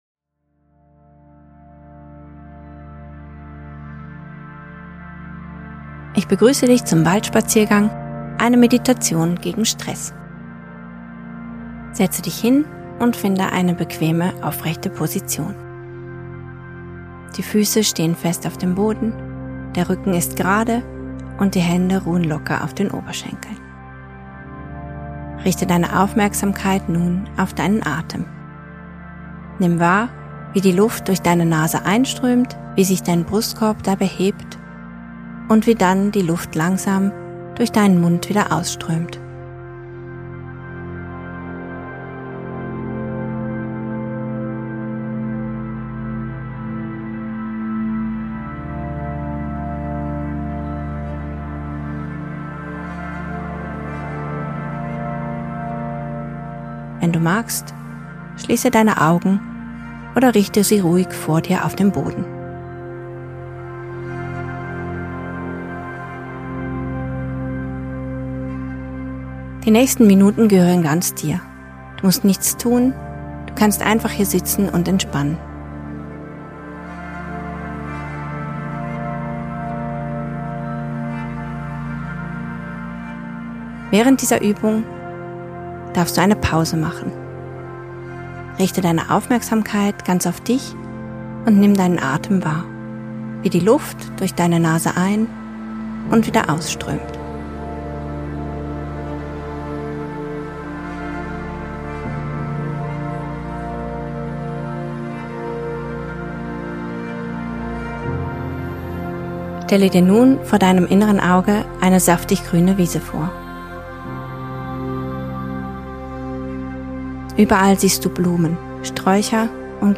Um im Alltag abzuschalten, kann ein Spaziergang durch den Wald Wunder bewirken. Mit dieser geleiteten Meditation kommt man zur Ruhe und entspannt sich.